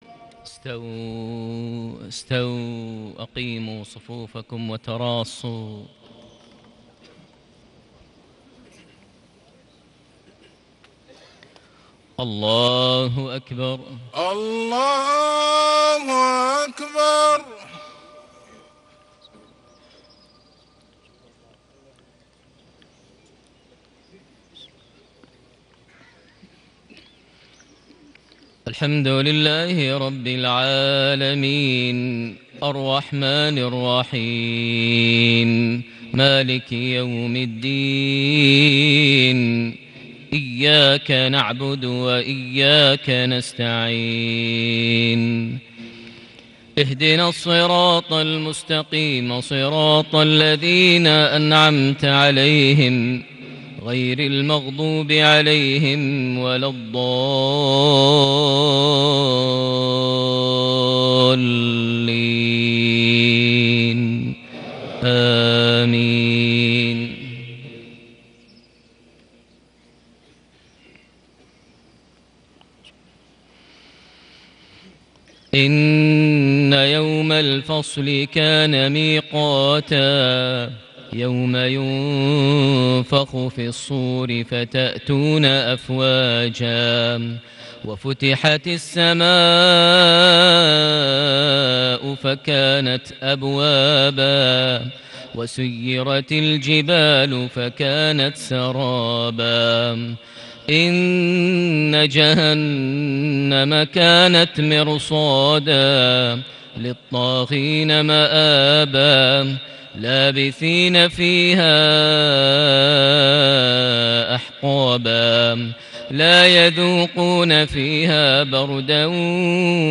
صلاة المغرب ١٤ جماد الأول ١٤٣٨هـ خواتيم سورتي النبأ / النازعات > 1438 هـ > الفروض - تلاوات ماهر المعيقلي